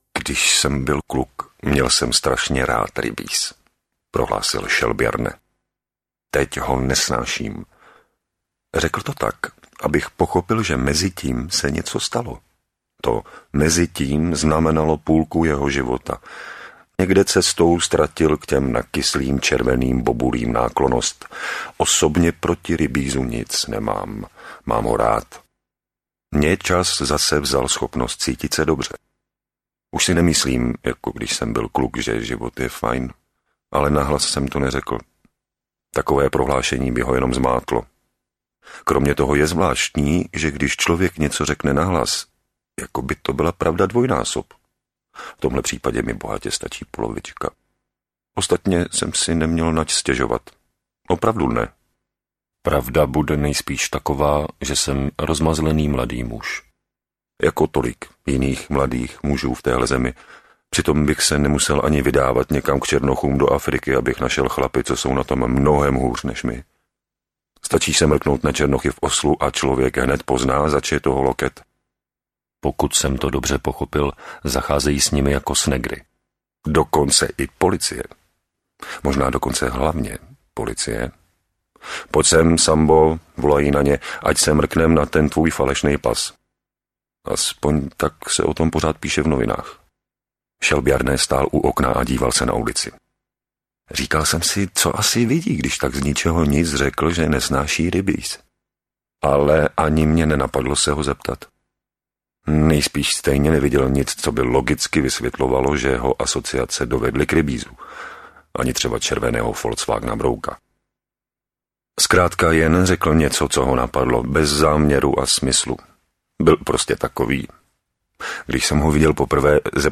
Ukázka z knihy
• InterpretJan Hájek
elling-pokrevni-bratri-audiokniha